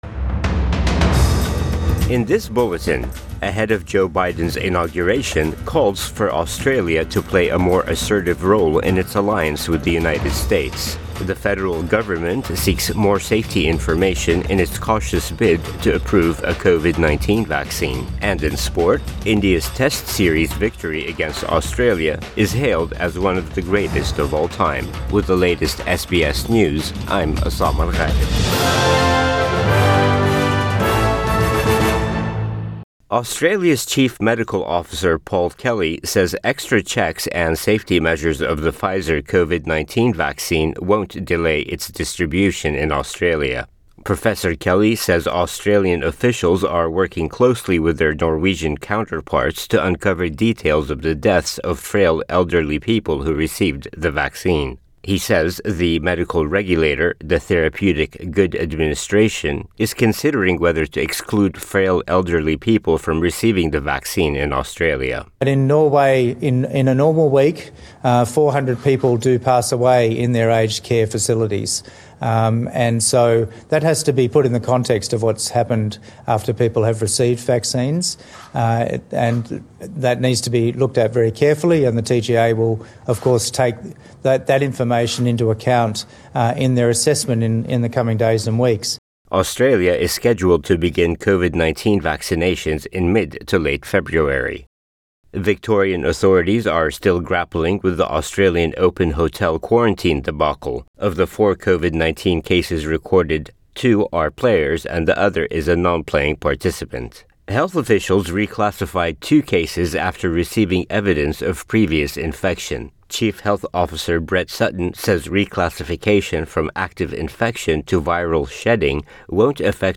AM bulletin 20 January 2021